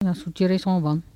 Langue Maraîchin
Patois - archives
Catégorie Locution